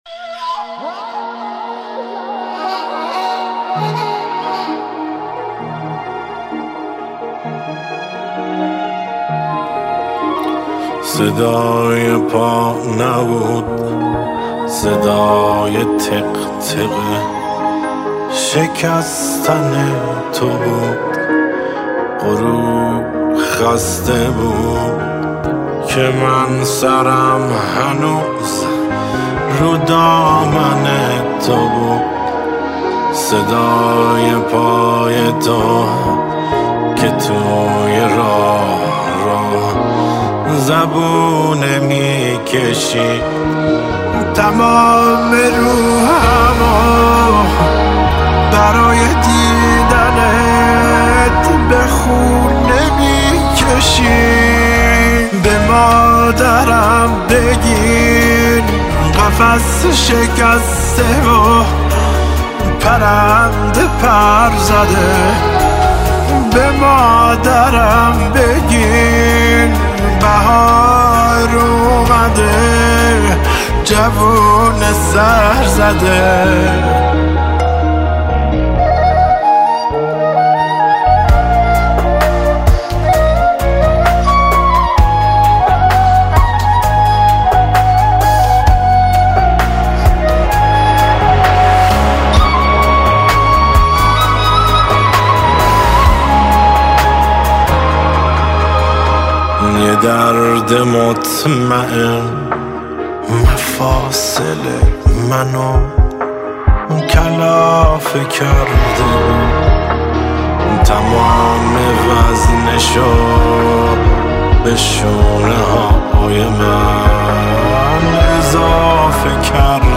آهنگ مادر غمگین قدیمی